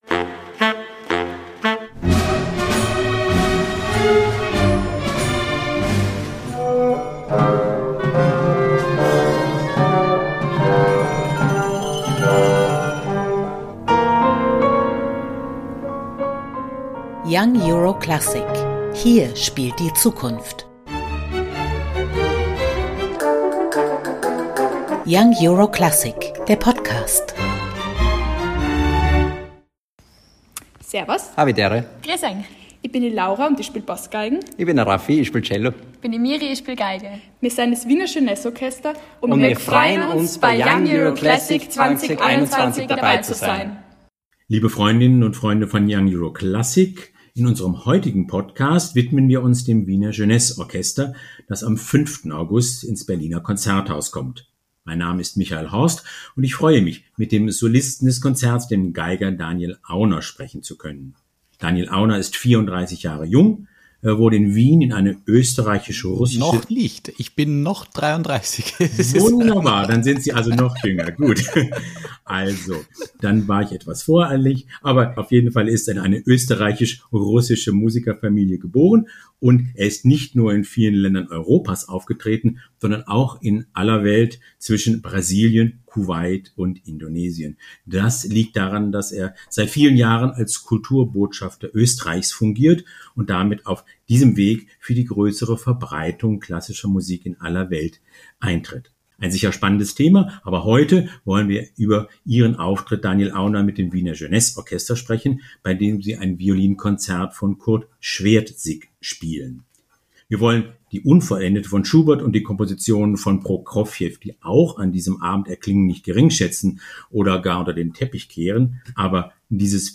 Das Gespräch